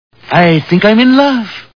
The Simpsons [Burns] Cartoon TV Show Sound Bites